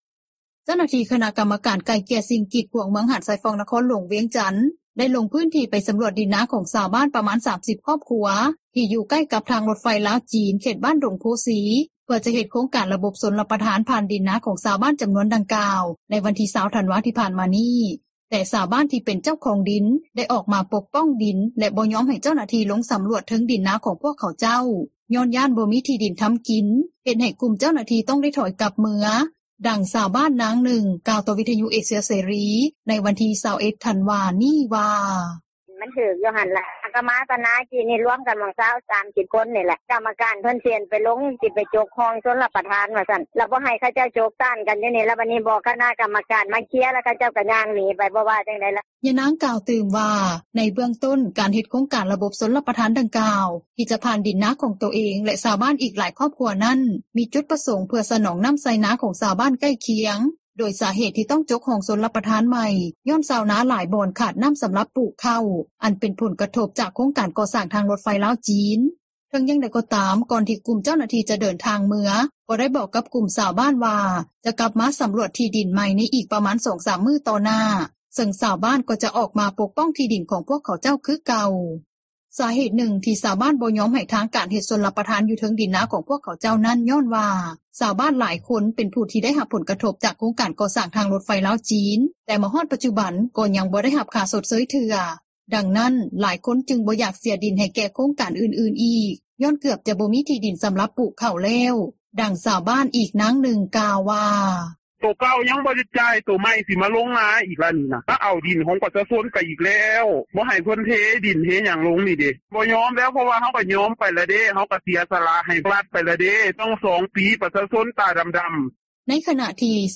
ສາເຫດນຶ່ງ ທີ່ຊາວບ້ານ ບໍ່ຍອມໃຫ້ທາງການເຮັດຊົລປະທານຢູ່ເທິງດິນນາຂອງພວກຂະເຈົ້ານັ້ນ ຍ້ອນວ່າ ຊາວບ້ານຫຼາຍຄົນເປັນຜູ້ໄດ້ຮັບຜົລກະທົບ ຈາກໂຄງການກໍ່ສ້າງທາງຣົຖໄຟລາວ-ຈີນ ແຕ່ມາຮອດປັດຈຸບັນ ກໍຍັງບໍ່ໄດ້ຮັບຄ່າຊົດເຊີຍເທື່ອ ດັ່ງນັ້ນ ຫຼາຍຄົນຈຶ່ງບໍ່ຢາກເສັຍທີ່ດິນໃຫ້ແກ່ໂຄງການອື່ນໆອີກ ຍ້ອນເກືອບຈະບໍ່ມີ ທີ່ດິນສໍາລັບປູກເຂົ້າແລ້ວ, ດັ່ງຊາວບ້ານ ອີກນາງນຶ່ງ ກ່າວວ່າ:
ທາງດ້ານເຈົ້າໜ້າທີ່ຂັ້ນທ້ອງຖິ່ນ ທ່ານນຶ່ງ ກ່າວວ່າ ທ່ານເອງກໍເຫັນໃຈຊາວບ້ານ ດັ່ງກ່າວ ທີ່ອອກມາປົກປ້ອງທີ່ດິນຂອງພວກຂະເຈົ້າ ຍ້ອນຫຼາຍຄົນເຄີຍເສັຍດິນນາໃຫ້ແກ່ໂຄງການກໍ່ສ້າງທາງຣົຖໄຟລາວ-ຈີນ ແຕ່ຍັງບໍ່ໄດ້ຮັບຄ່າຊົດເຊີຍ ຈຶ່ງບໍ່ມີໃຜຢາກເສັຍທີ່ດິນຕື່ມອີກ ເພາະຈະບໍ່ມີທີ່ດິນທໍາກິນ, ດັ່ງທ່ານກ່າວວ່າ: